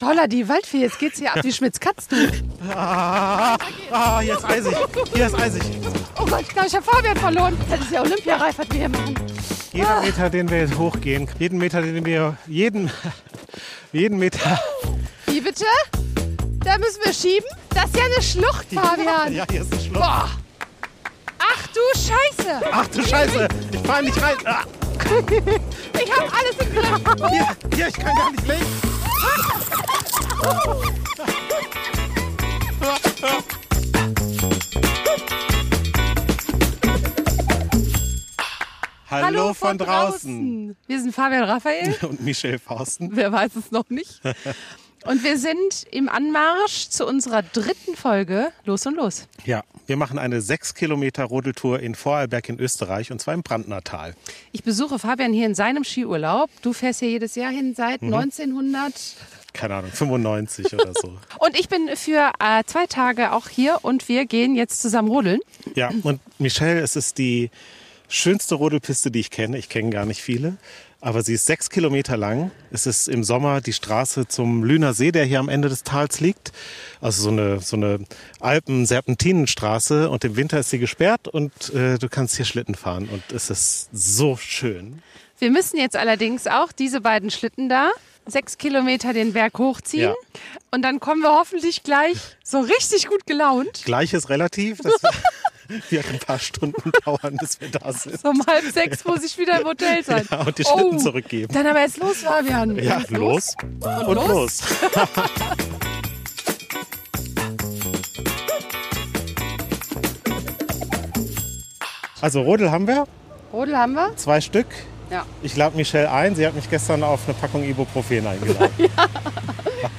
Sause mit uns ganze sechs Kilometer auf einer perfekten Rodelbahn durch die einsame Bergwelt im Talschluss des Brandnertals in Vorarlberg (Österreich). Wir hatten (hörbar) den Spaß unseres Lebens!